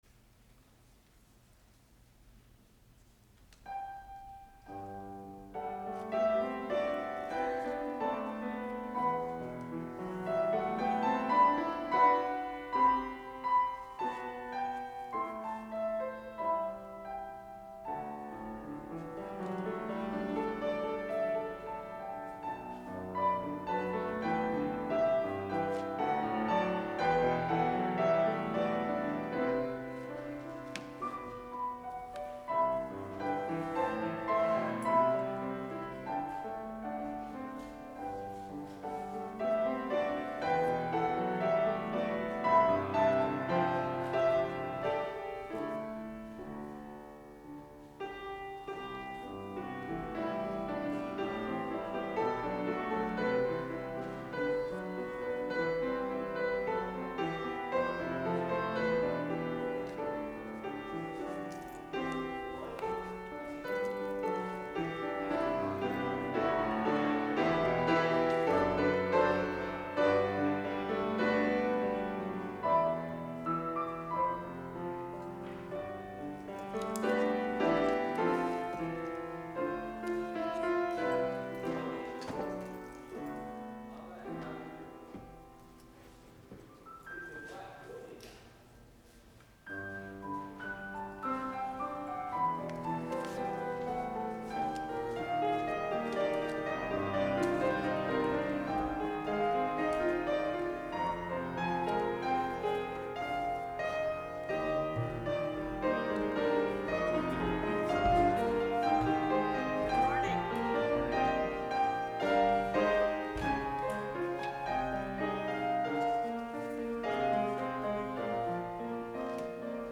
Please click below for audio recording of this worship service.